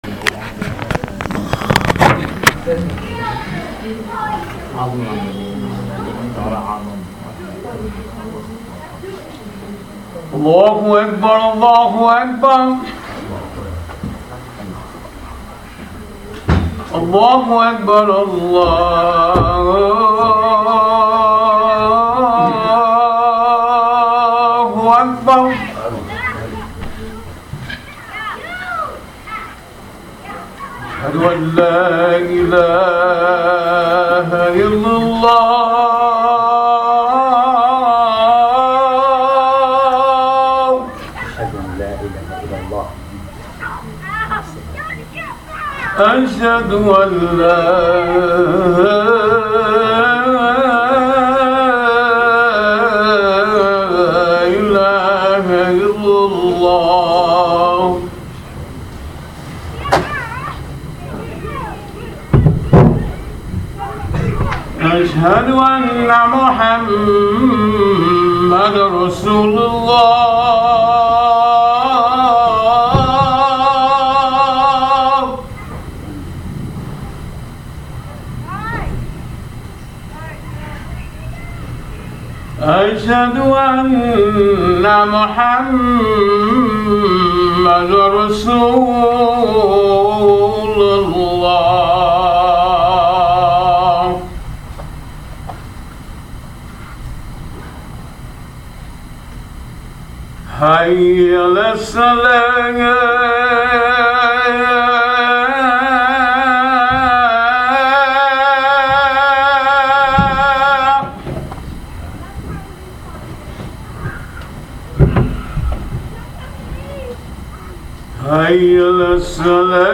Audio Khutba: Don’t be fooled! Be Firm in Your Faith!
Do not be a witness to these things without taking a stand. Now is not the time to be shaky in your faith. I spoke about this during last week’s khutbatul Jum’ah.